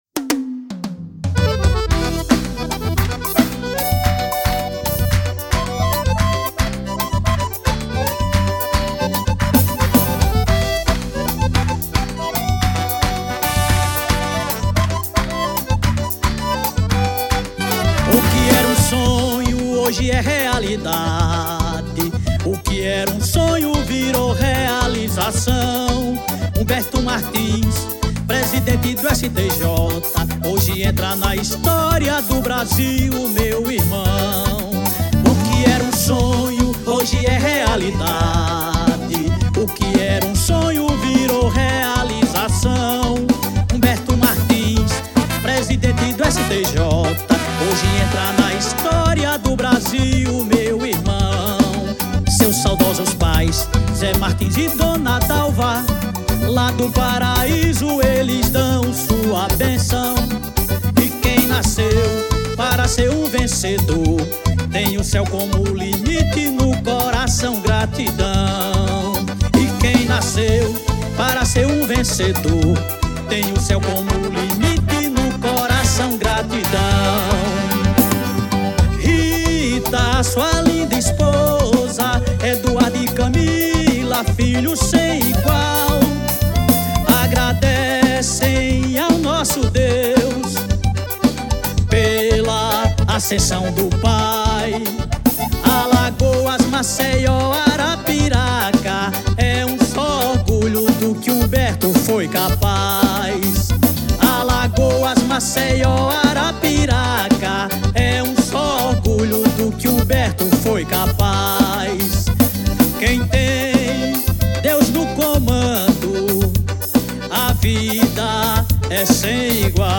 O forró do presidente do STJ
personagem de um forró que começou a circular nos grupos de WhatsApp do meio jurídico de Brasília nesta quarta.